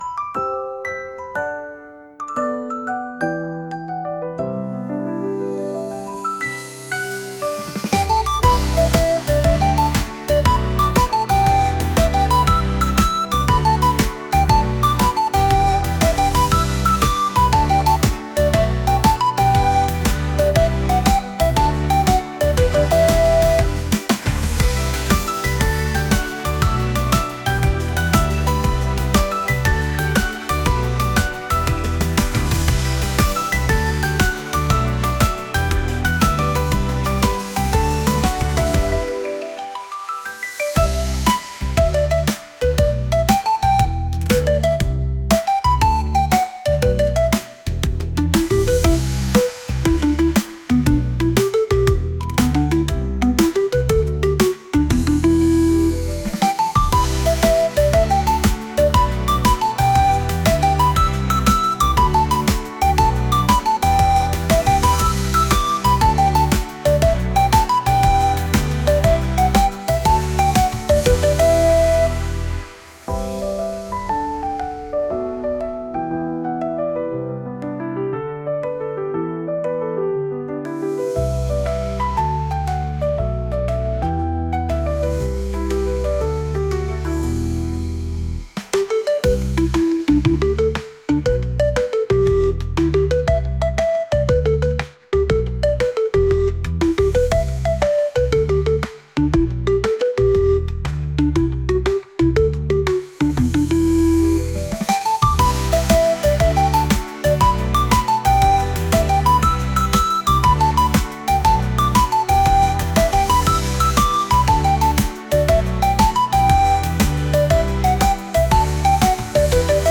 リコーダーで寂しさを感じつつもしっかりとした力強さを感じる音楽です。